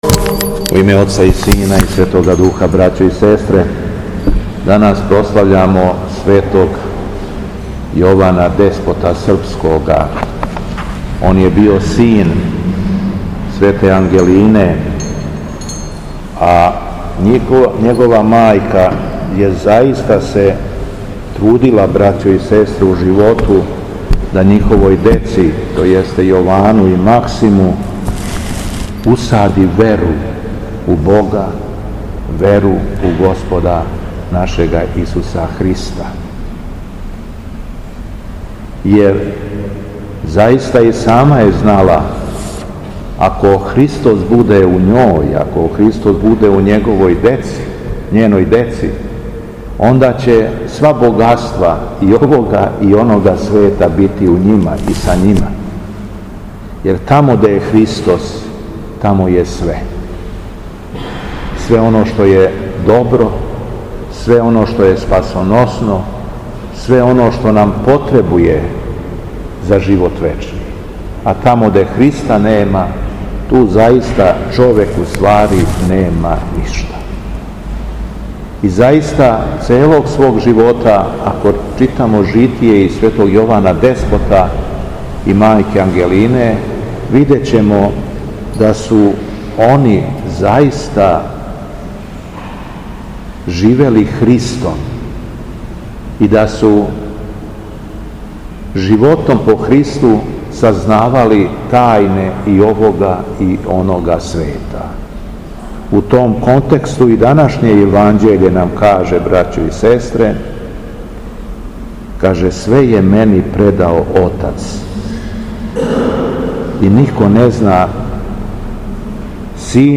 У понедељак, 23. децембра 2024. године, када наша света Црква прославља светог Јована деспота српског, Његово Високопресвештенство Митрополит шумадијски Господин Јован служио је свету архијерејску литургију у храму Светога Саве у крагујевачком насељу Аеродром.
Беседа Његовог Високопреосвештенства Митрополита шумадијског г. Јована